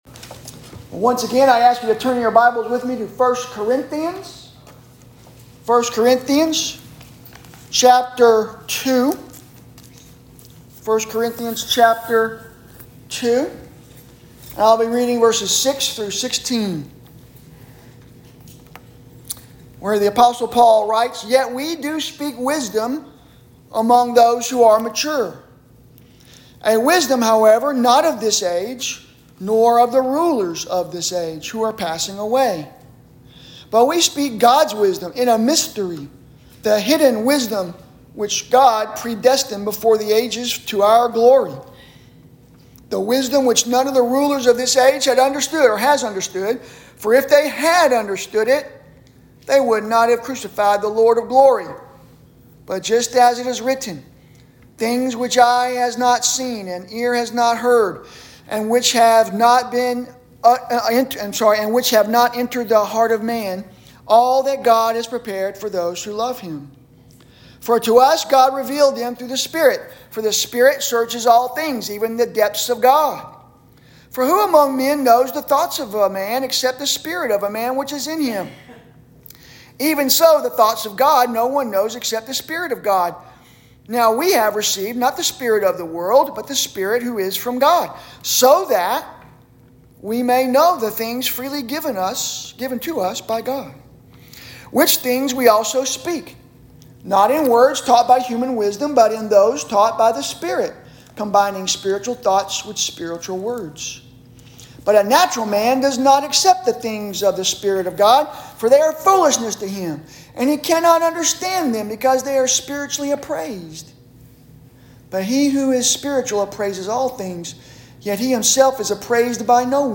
Sermons – First Baptist Church